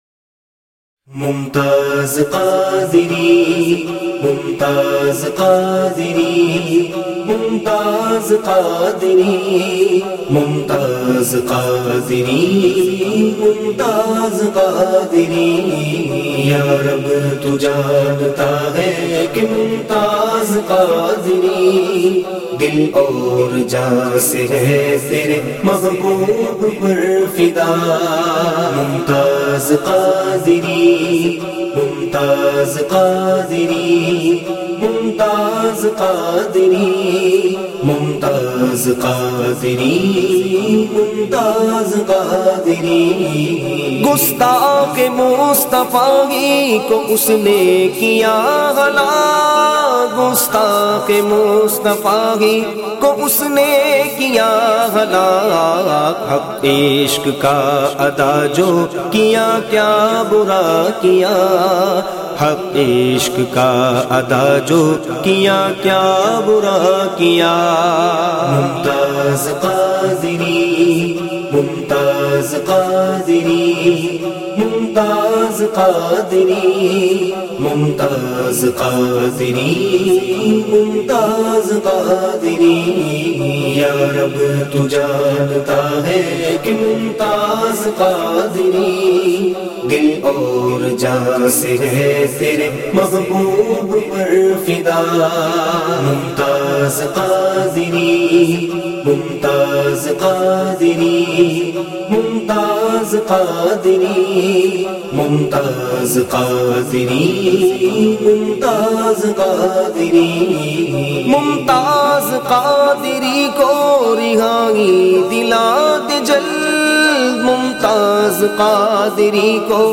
کلام